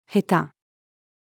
下手-female.mp3